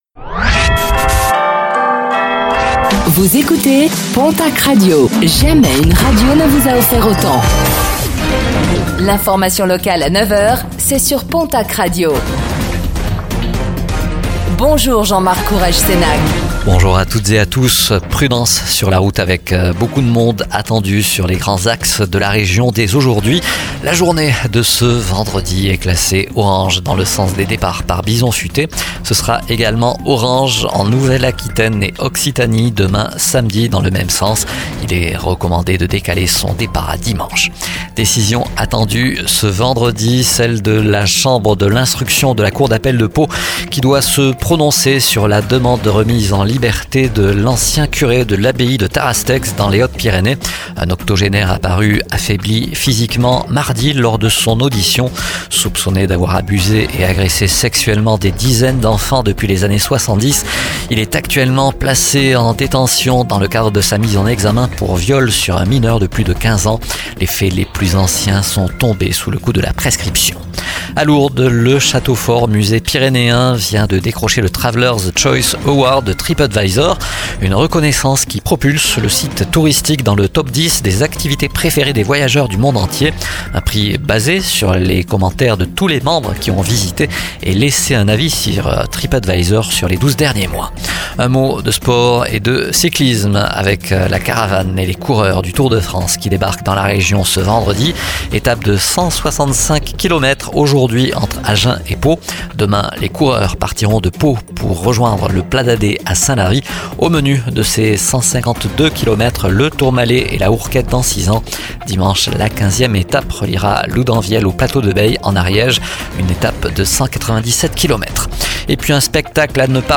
Réécoutez le flash d'information locale de ce vendredi 12 juillet 2024